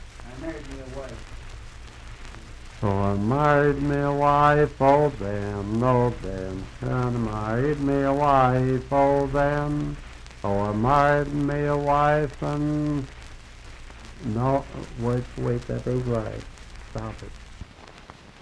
I Married Me A Wife - West Virginia Folk Music | WVU Libraries
Unaccompanied vocal music
Voice (sung)